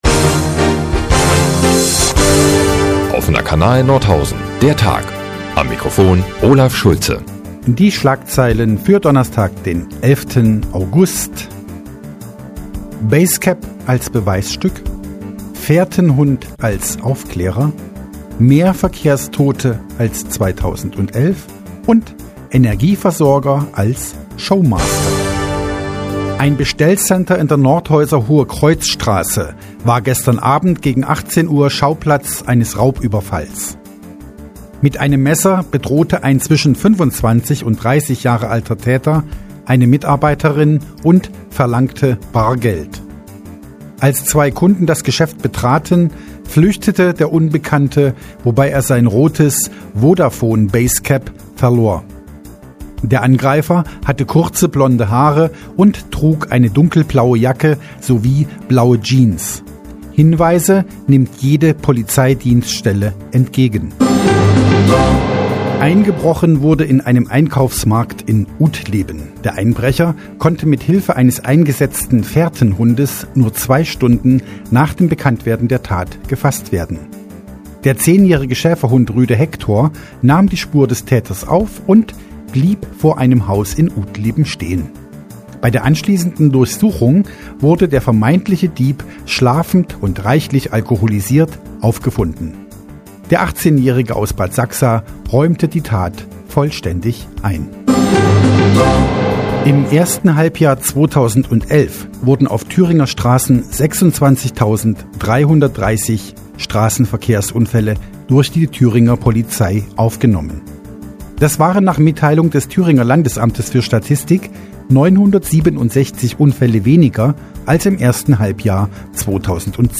Die tägliche Nachrichtensendung des OKN ist nun auch in der nnz zu hören. Heute steht ein Fährtenhund im Mittelpunkt, die Spieder Murphy Gang, ein rotes Basecap und eine Verkehrsstatistik.